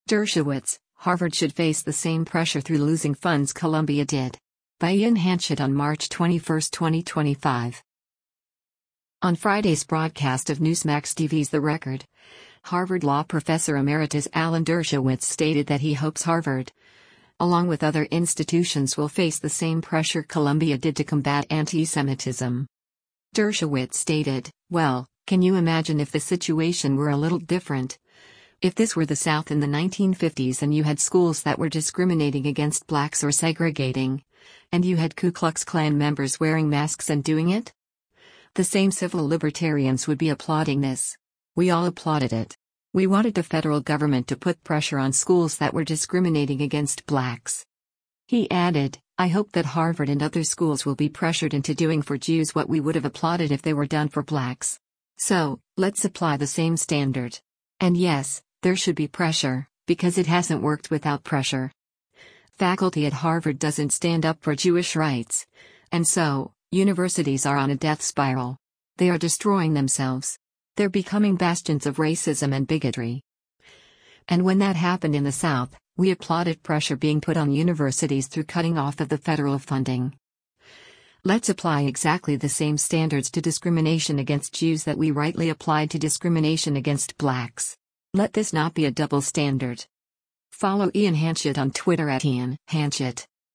On Friday’s broadcast of Newsmax TV’s “The Record,” Harvard Law Professor Emeritus Alan Dershowitz stated that he hopes Harvard, along with other institutions will face the same pressure Columbia did to combat antisemitism.